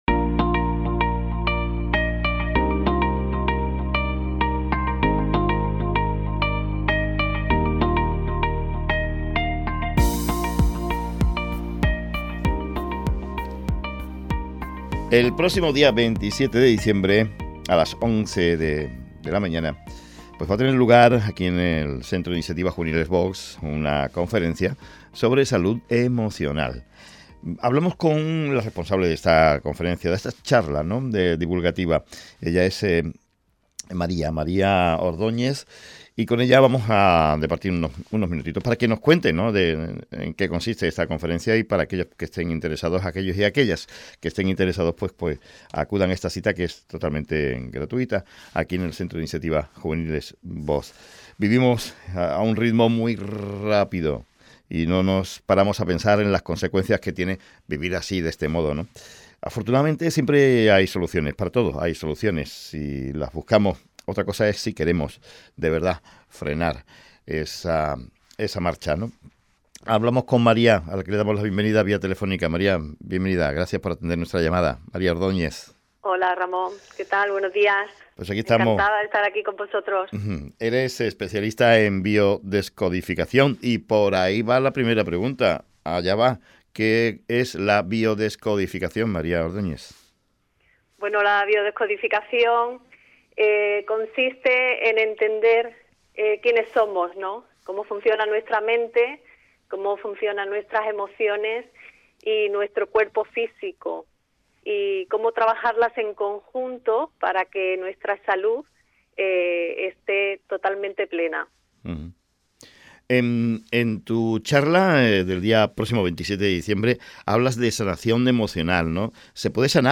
Entrevista en Radio Chiclana sobre Salud Emocional.